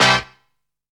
LAST STAB.wav